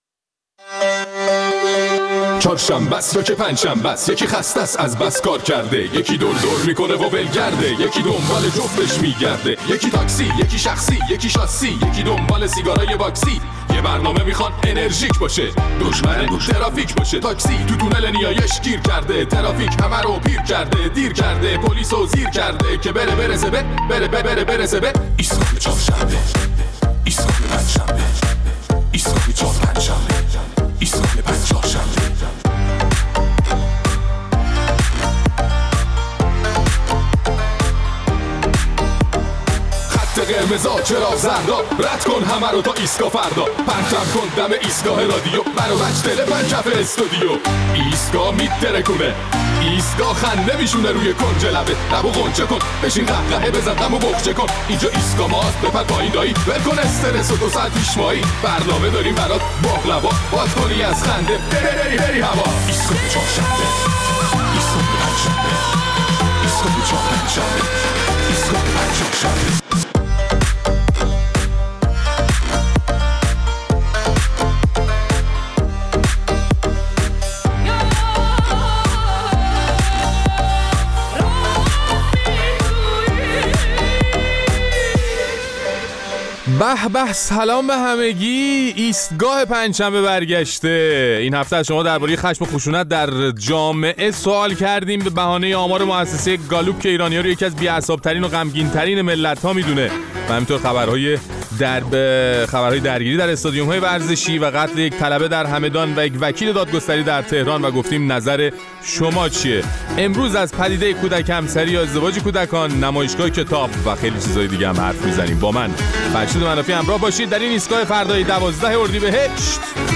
در این برنامه ادامه نظرات شنوندگان‌مان را درباره چرایی گسترش خشم و خشونت در جامعه می‌شنویم.